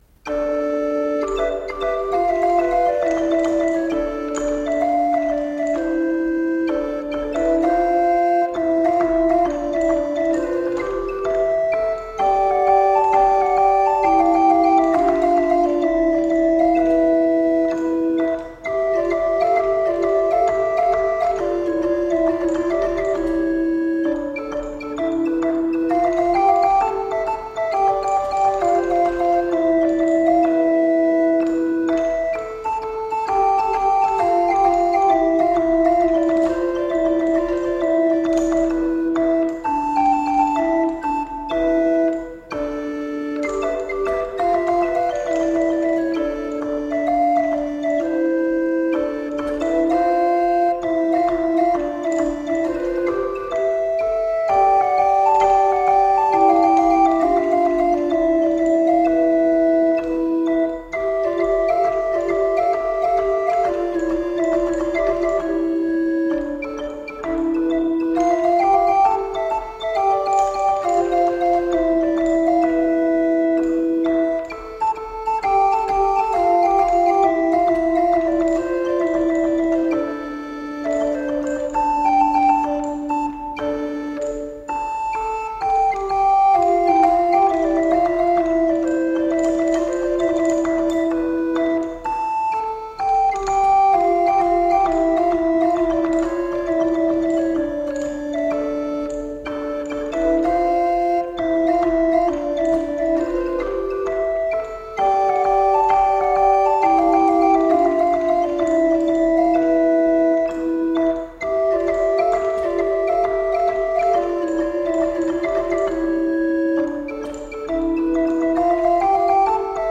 Medieval Dance Music.